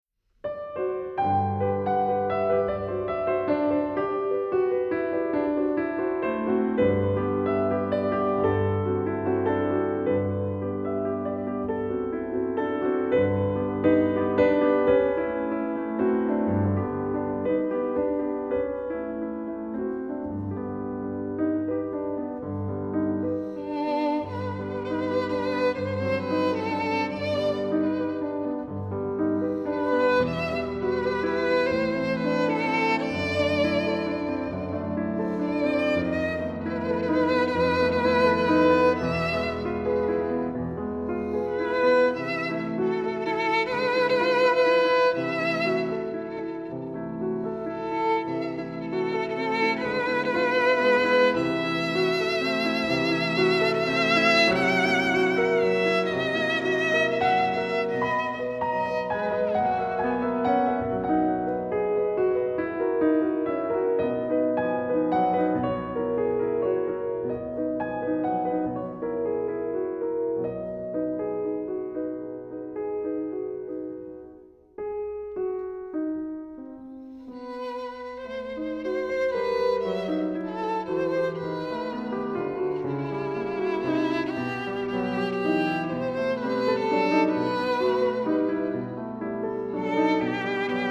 ★出身音樂世家的優秀小提琴新銳，與瑞士鋼琴家聯手合奏，絕佳默契無人能比！